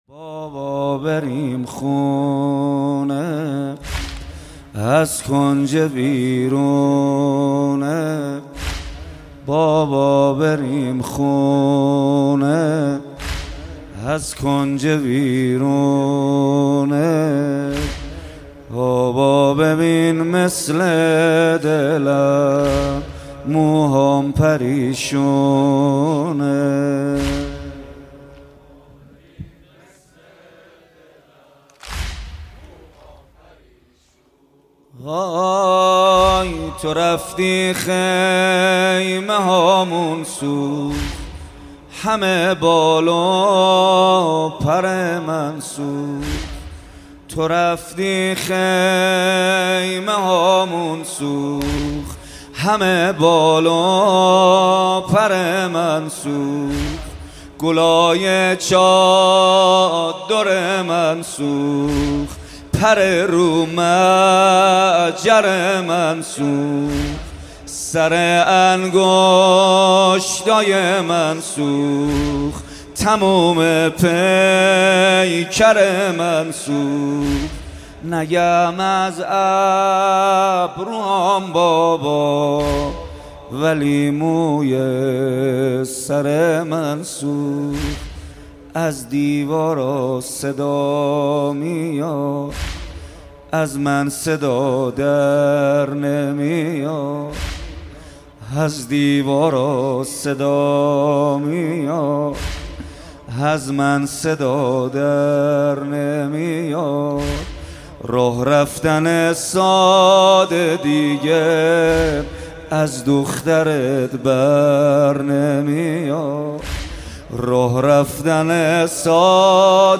مداحی جدید
شب سوم محرم 1400